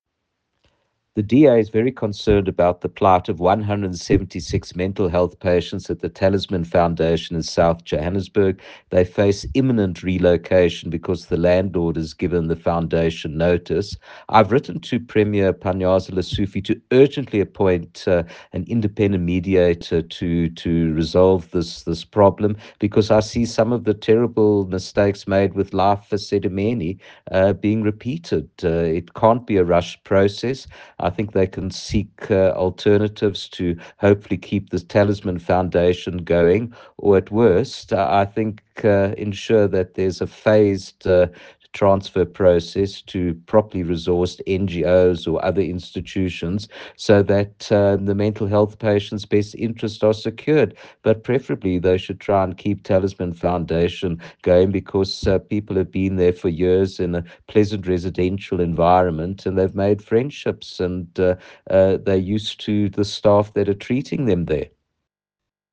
Note to Editors: Attached please find a soundbite in English by Dr Jack Bloom